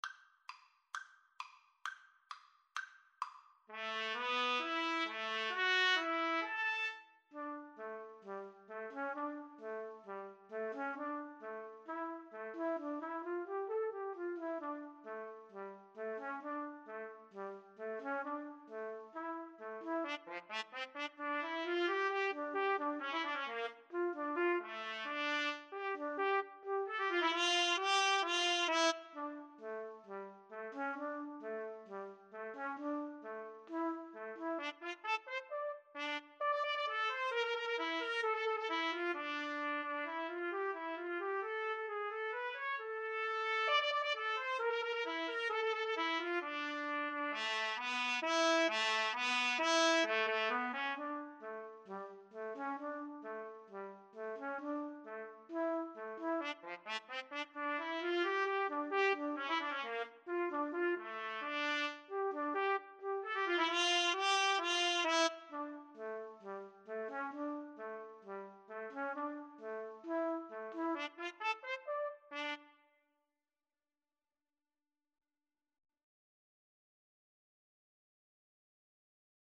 D minor (Sounding Pitch) E minor (Trumpet in Bb) (View more D minor Music for Trumpet Duet )
2/4 (View more 2/4 Music)
Traditional (View more Traditional Trumpet Duet Music)